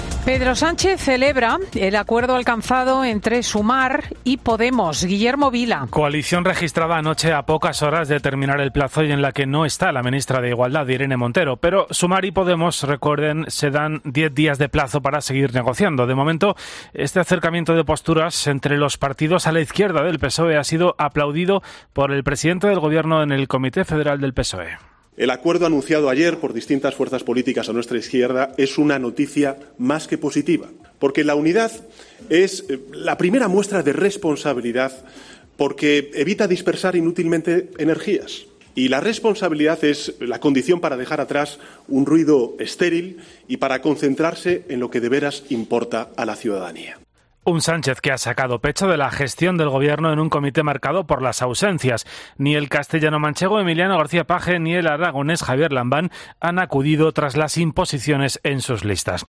Estas palabras son parte del discurso de Pedro Sánchez en la apertura del Comité Federal que el PSOE celebra este sábado en su sede de Ferraz para ratificar las listas al Congreso y al Senado para las próximas elecciones generales del 23J.